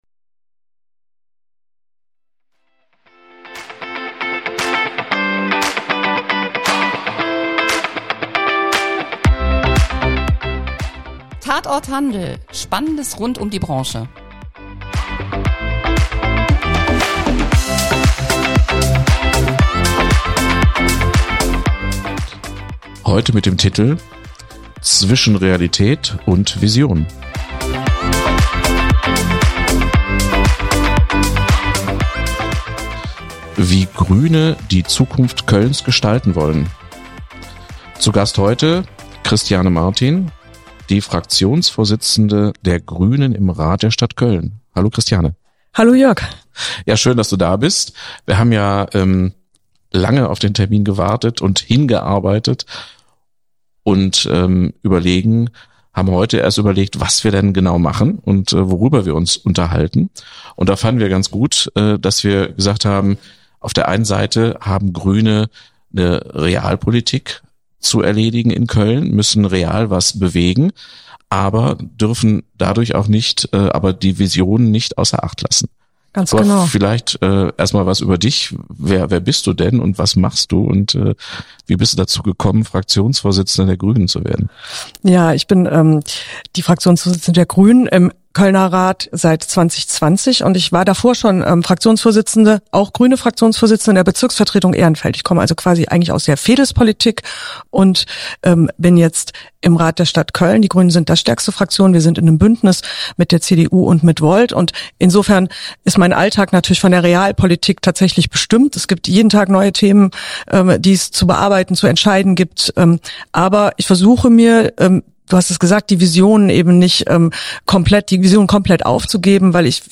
Christiane Martin, Fraktionsvorsitzende der Grünen im Rat der Stadt Köln, im Gespräch mit dem Handelsverband